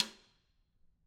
Snare2-taps_v4_rr2_Sum.wav